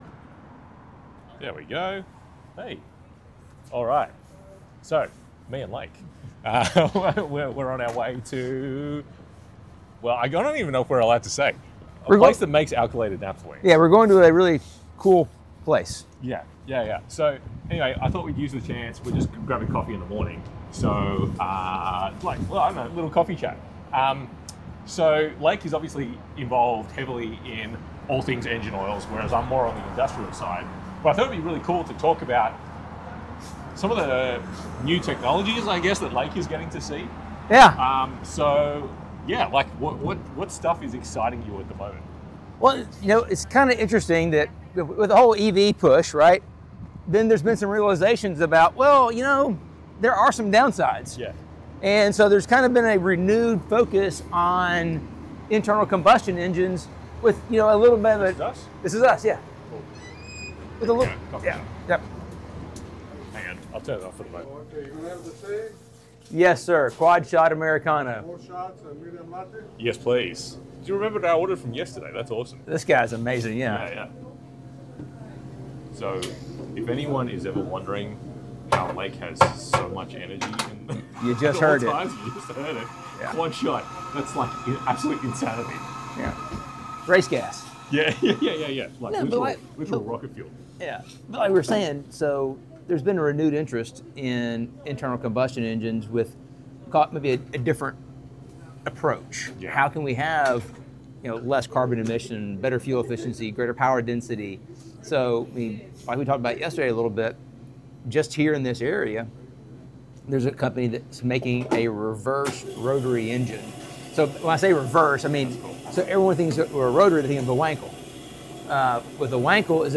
In this casual coffee chat, we delve into the renewed interest in internal combustion engines, the unique reverse rotary engine by Liquid Piston, and the innovative Aqua Stroke engine running on 70% water and 30% ethanol. We also discuss the future of engine oils, the impact of alternative fuels like hydrogen and ammonia, and get a glimpse into the evolving landscape of racing engines with insights from NASCAR and Formula 1.